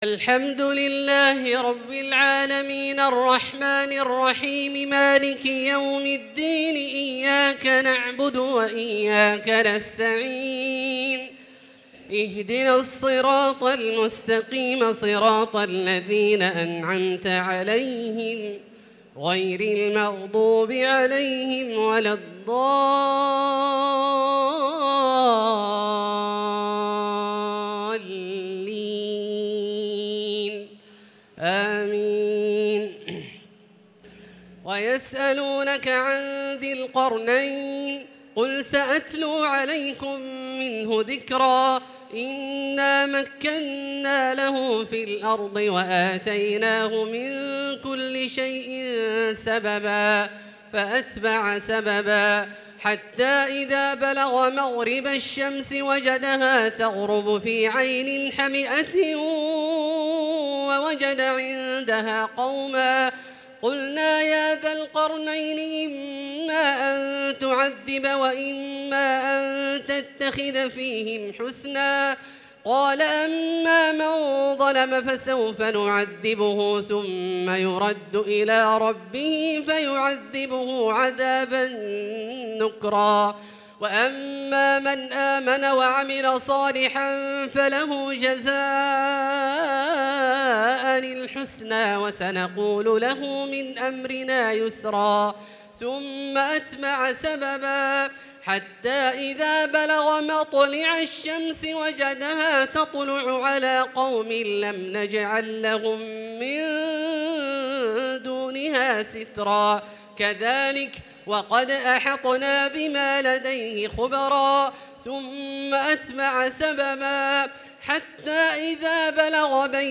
صلاة التراويح ليلة 21 رمضان 1443 للقارئ عبدالله الجهني - الثلاث التسليمات الأولى صلاة التراويح